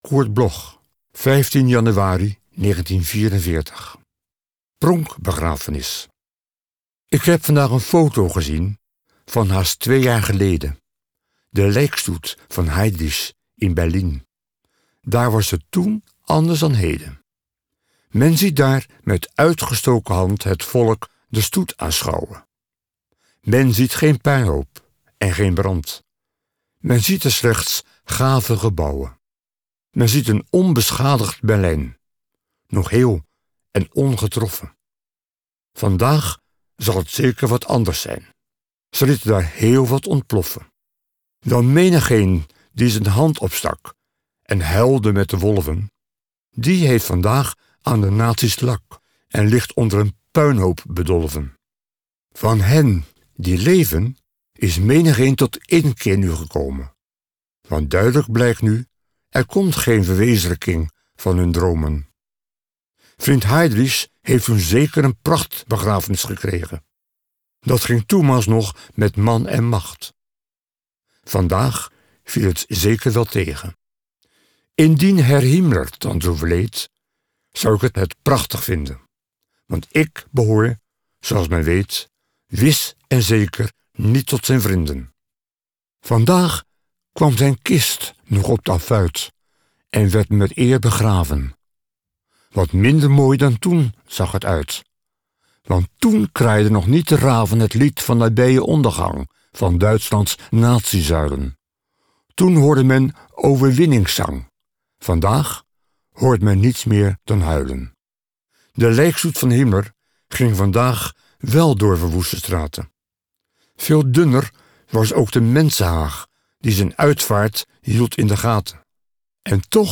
Recording: MOST, Amsterdam · Editing: Kristen & Schmidt, Wiesbaden